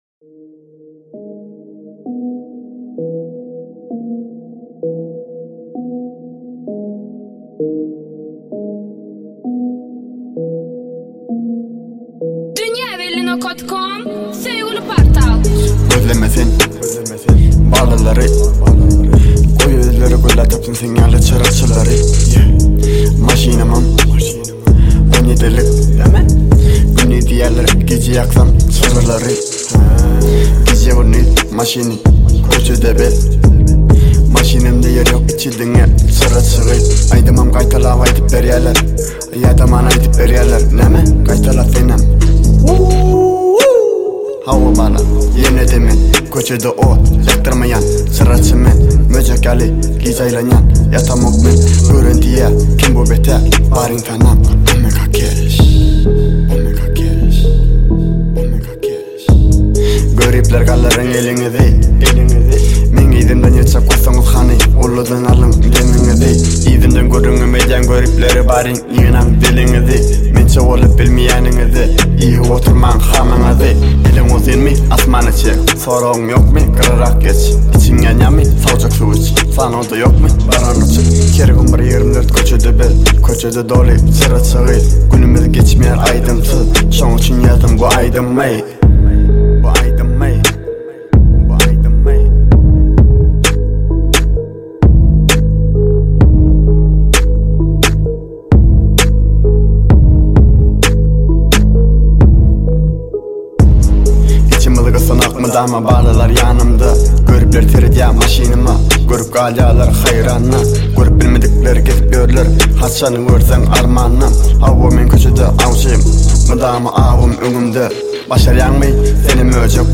Bölüm: Türkmen Aýdymlar / Rep